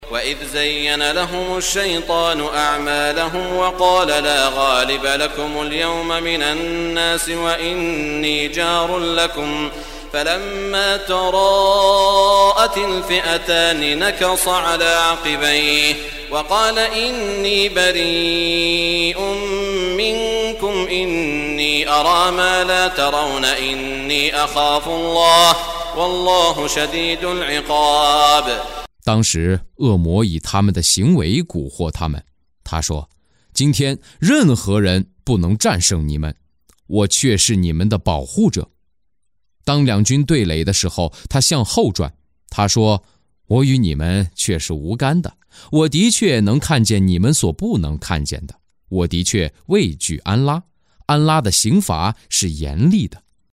中文语音诵读的《古兰经》第（安法里）章经文译解（按节分段），并附有诵经家沙特·舒拉伊姆的朗诵